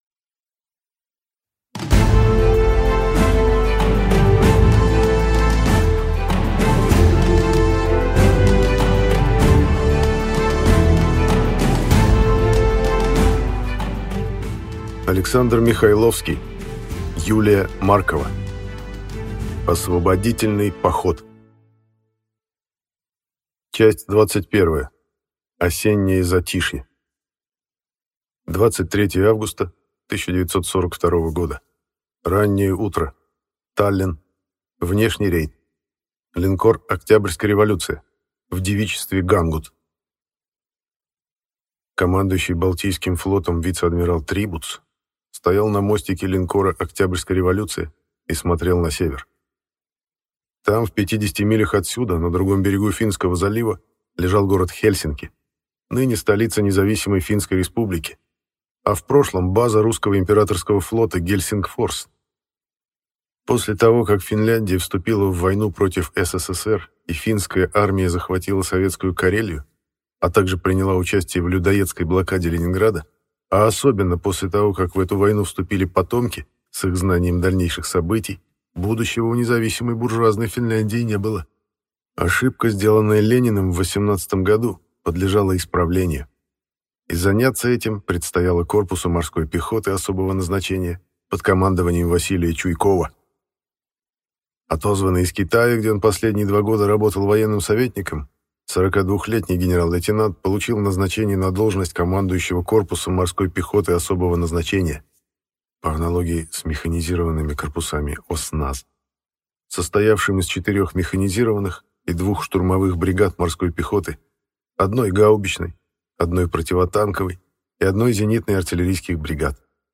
Аудиокнига Освободительный поход | Библиотека аудиокниг